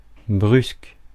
Ääntäminen
France: IPA: /bʁysk/